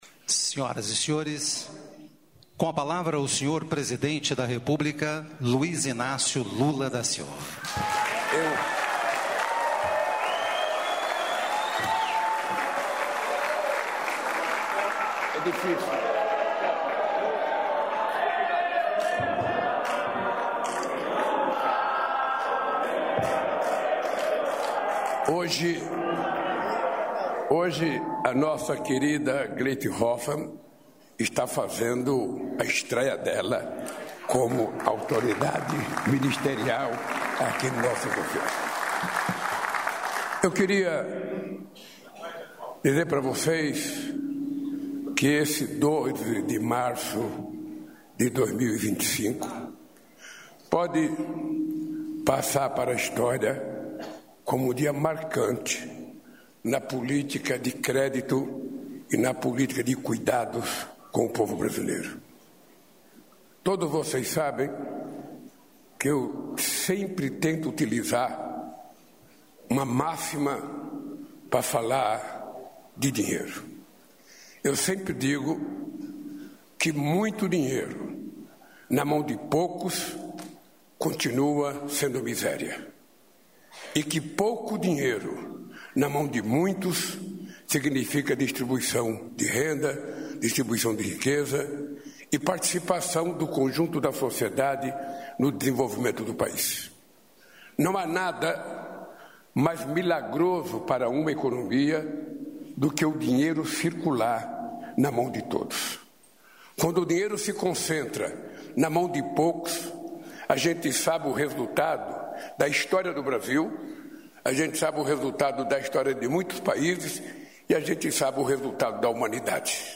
Ouça a íntegra da declaração do presidente Luiz Inácio Lula da Silva, do Comandante da Aeronáutica, Tenente-Brigadeiro do Ar Marcelo Kanitz Damasceno, do embaixador do Líbano e da primeira-dama Janja da Silva, neste domingo (6), na chegada do primeiro voo da FAB de repatriação de brasileiros à Base Aérea de São Paulo, em Guarulhos.